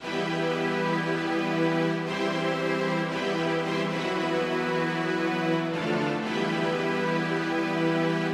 描述：惊人的大提琴循环
标签： 115 bpm Orchestral Loops Strings Loops 1.40 MB wav Key : Em FL Studio
声道立体声